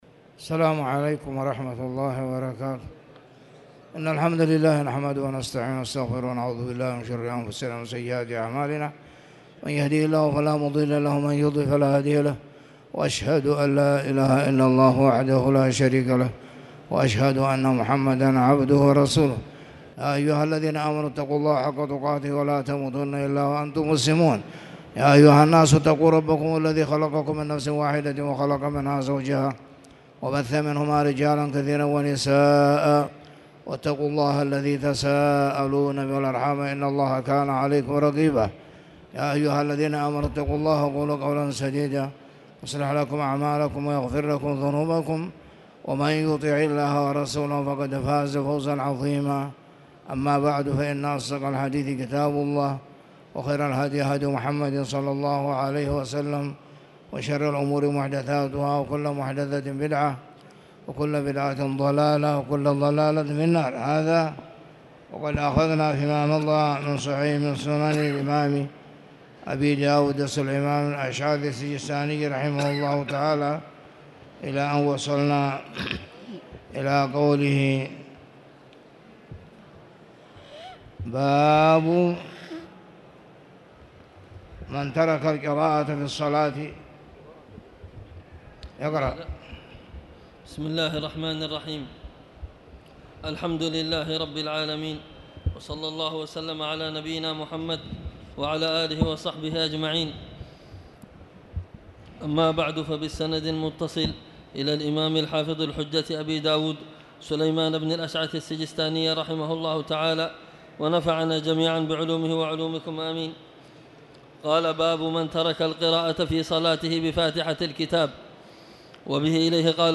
تاريخ النشر ١٥ صفر ١٤٣٨ هـ المكان: المسجد الحرام الشيخ